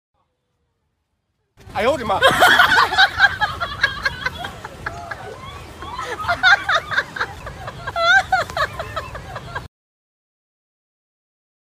抖音搞笑哎呦我的妈呀音效_人物音效音效配乐_免费素材下载_提案神器
抖音搞笑哎呦我的妈呀音效免费音频素材下载